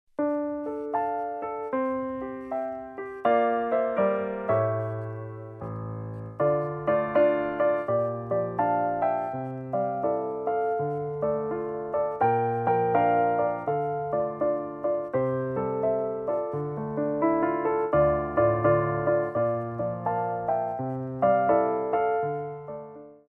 U.K. Artist / Accompanist
Piano Arrangements of Pop & Rock for Tap Class
SLOW TEMPO